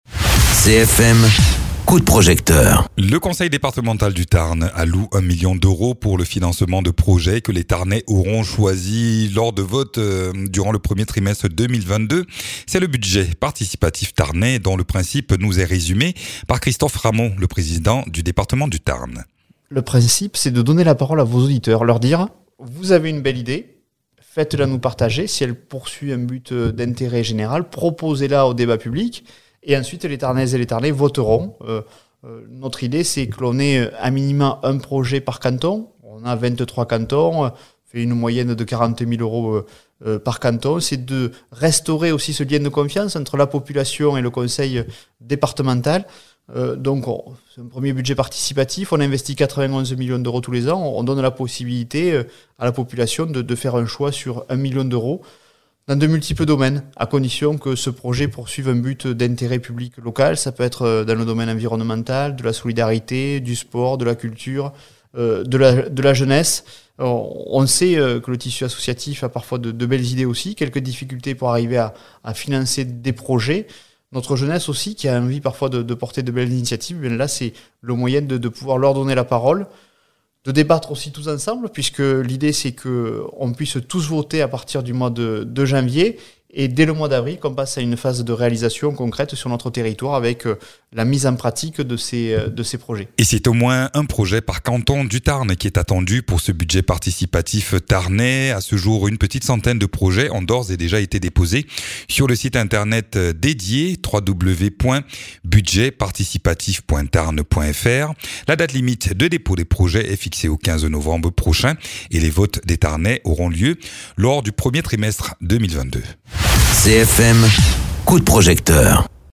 Interviews
Invité(s) : Christophe Ramond, président du conseil départemental du Tarn.